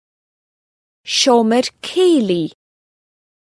Amazon AWS (Scottish [!] pronunciation).